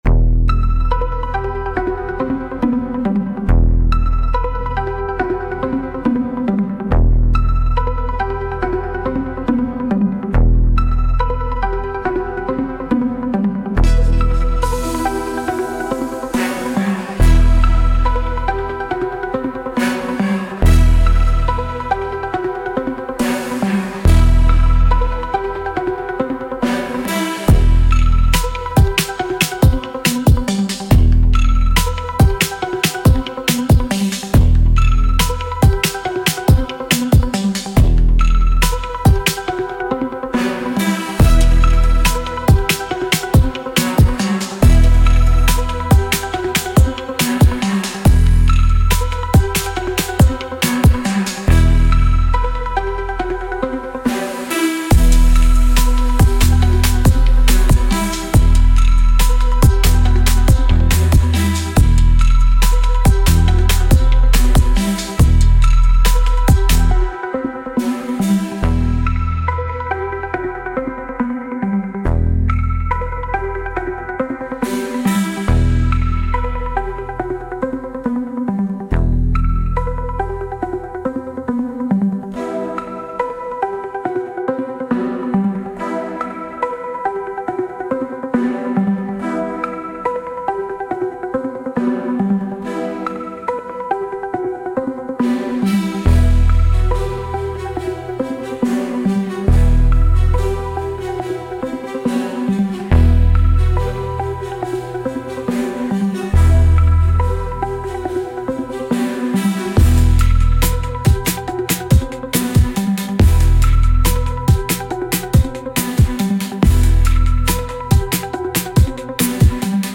Instrumental - The Streamer’s Blade